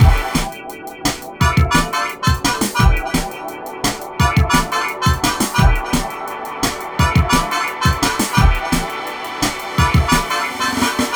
13 Breakdance-b.wav